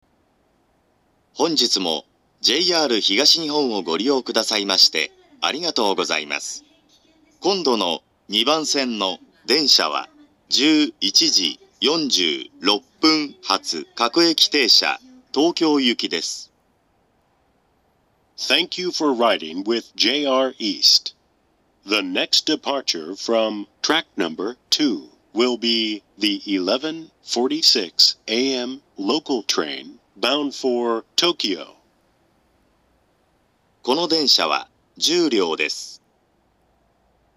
２番線到着予告放送
shinkiba2bansen-jihatu2.mp3